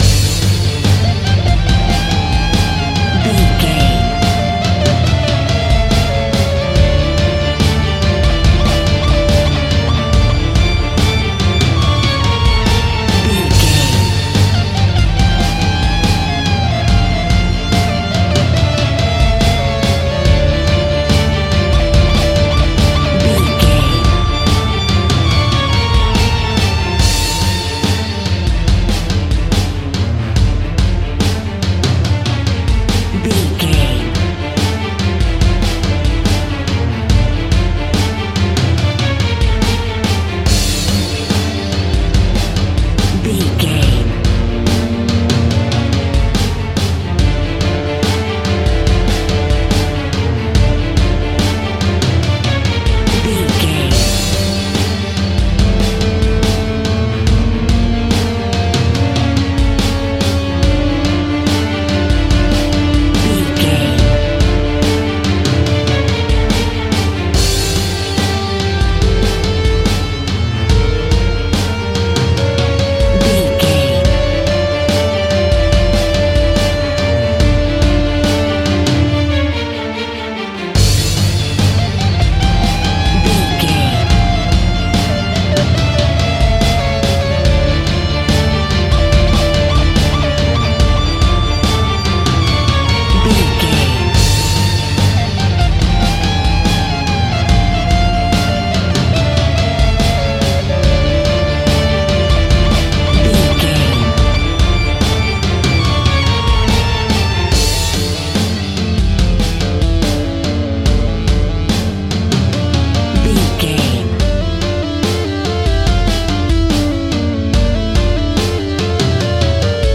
In-crescendo
Aeolian/Minor
Fast
tension
ominous
dark
strings
electric guitar
drums
bass guitar
synthesizer
horror
Synth Pads